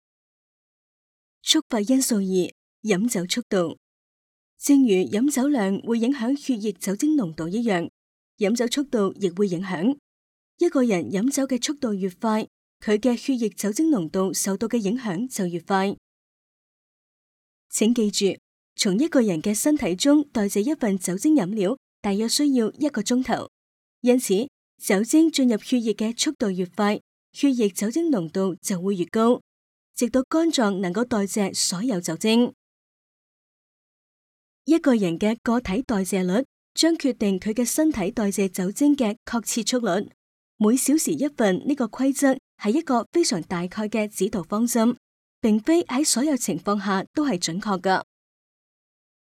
Cantonese_Female_003VoiceArtist_10Hours_High_Quality_Voice_Dataset